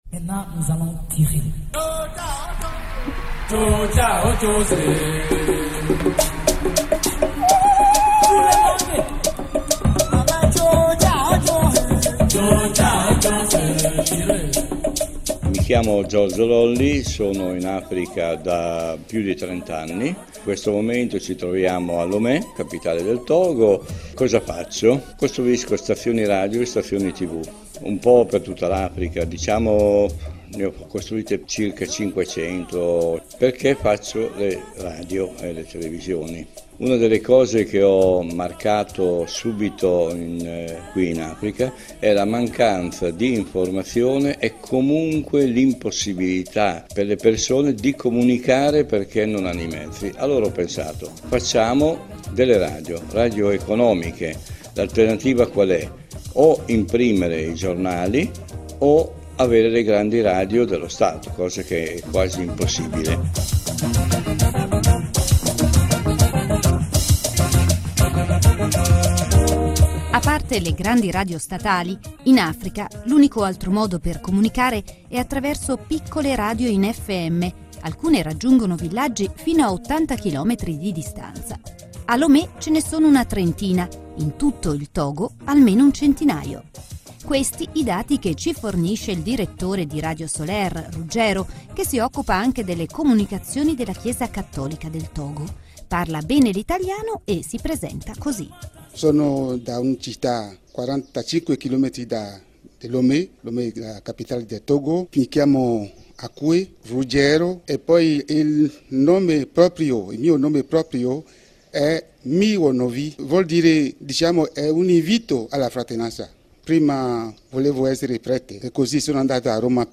Togo in FM / Intervista